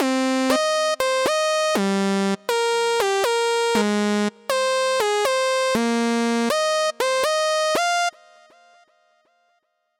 The pattern is tightened up using some manual editing.
The new tighter edited MIDI.